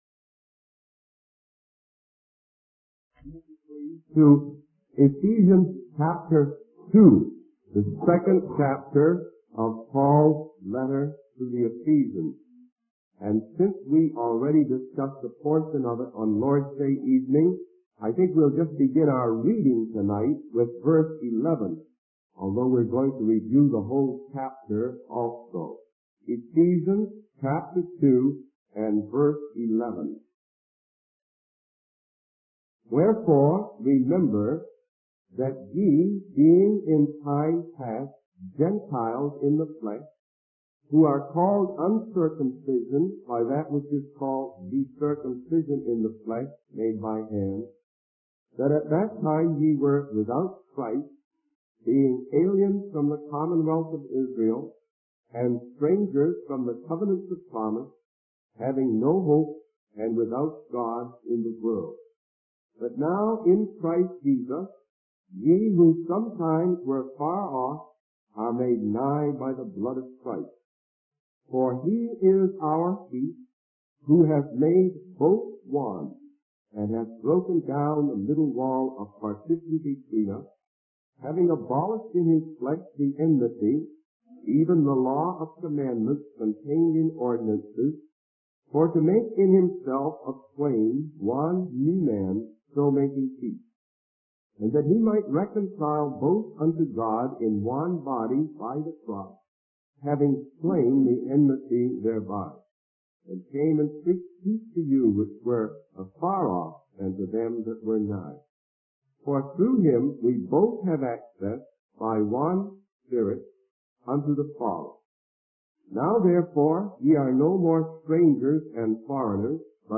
In this sermon on Hebrews chapter 2, the preacher emphasizes the importance of paying close attention to the word of God.